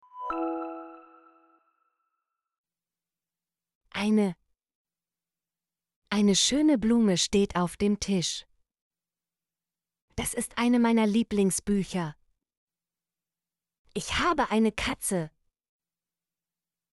eine - Example Sentences & Pronunciation, German Frequency List